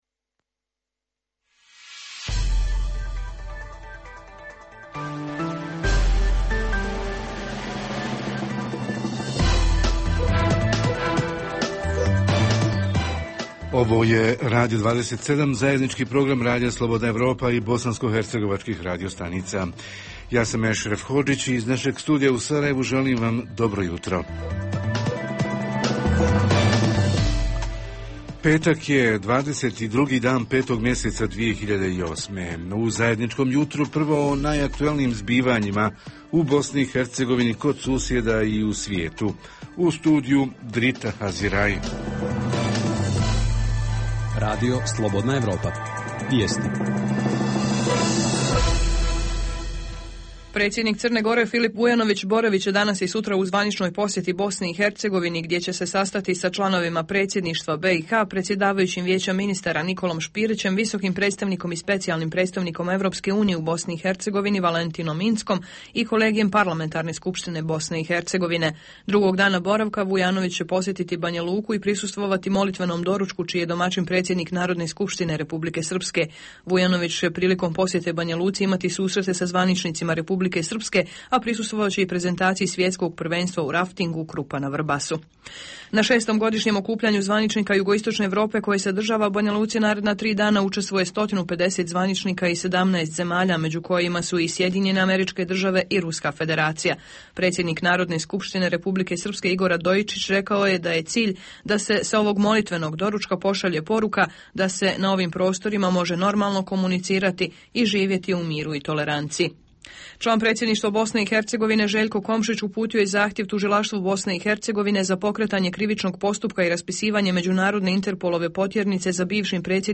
Jutarnji program za BiH koji se emituje uživo pita: profesionalna orijentacija – ko će i kako svršenim osnovcima pomoći u izboru nastavka obrazovanja? Reporteri iz cijele BiH javljaju o najaktuelnijim događajima u njihovim sredinama.
Redovni sadržaji jutarnjeg programa za BiH su i vijesti i muzika.